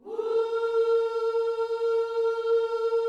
WHOO A#4C.wav